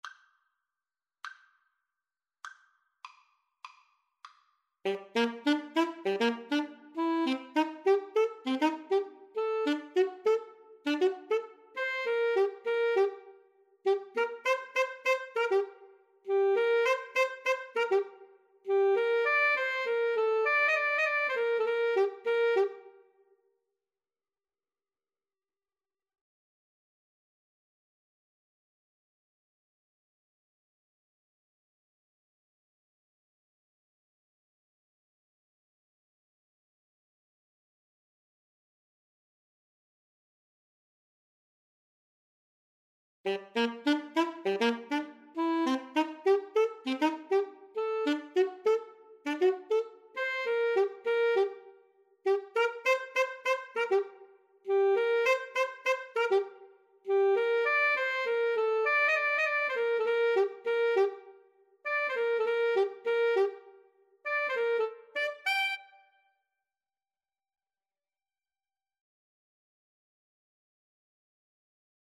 4/4 (View more 4/4 Music)
Jazz (View more Jazz Alto-Tenor-Sax Duet Music)